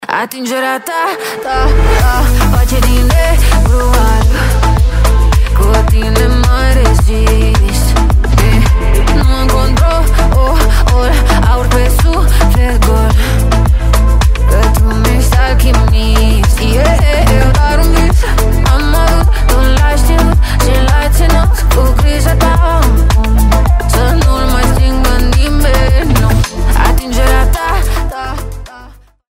Categorie: Hip-Hop